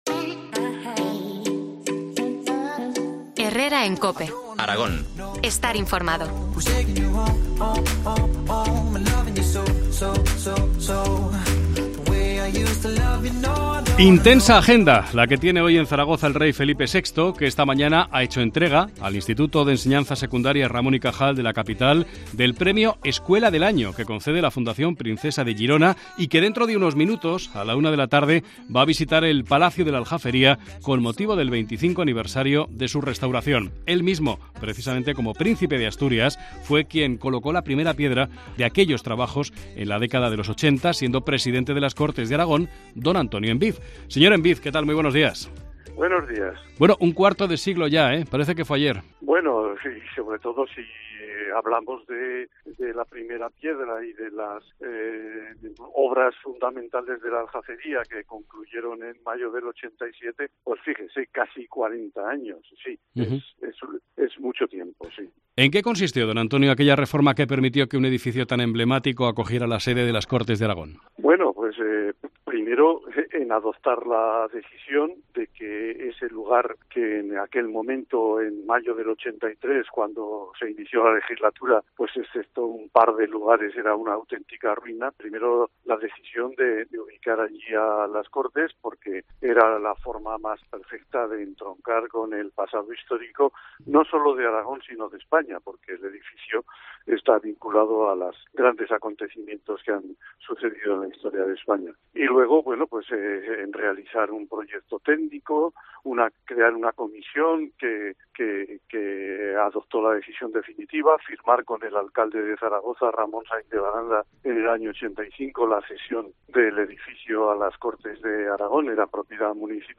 Entrevista al ex presidente de las Cortes, Antonio Embid, sobre la reforma del Palacio de la Aljafería.